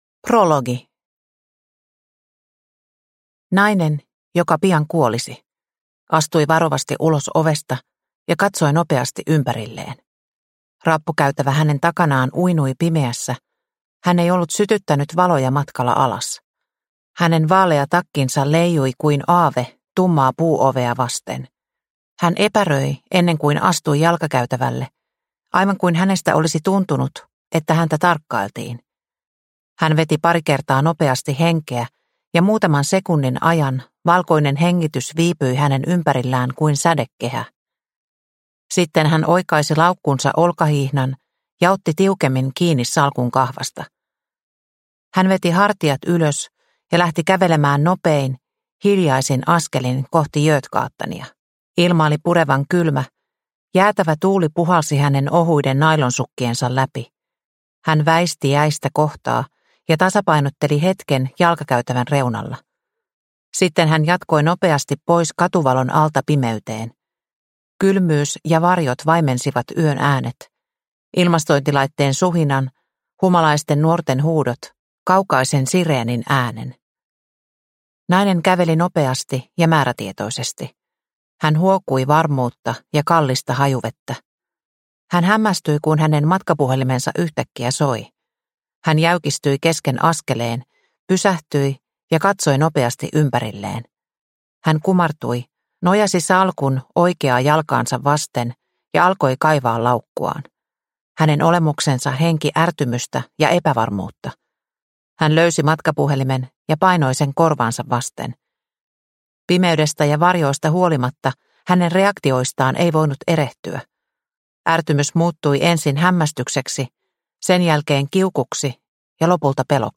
Uutispommi – Ljudbok – Laddas ner